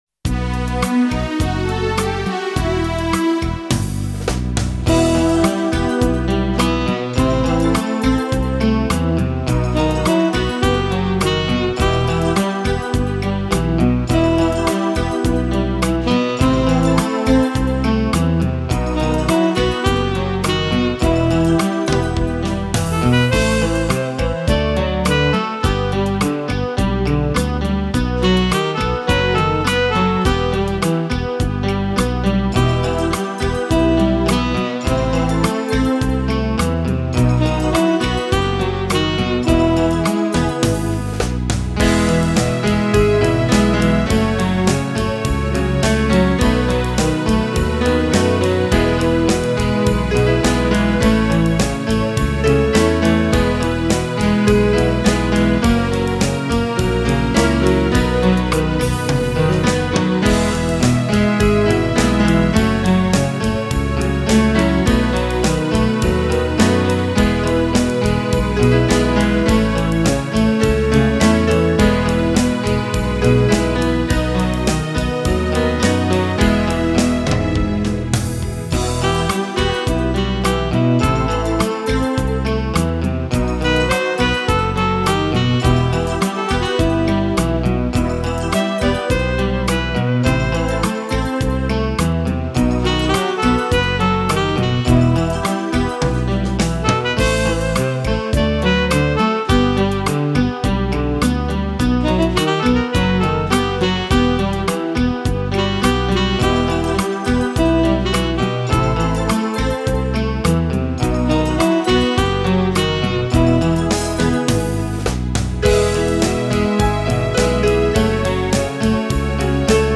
Boléro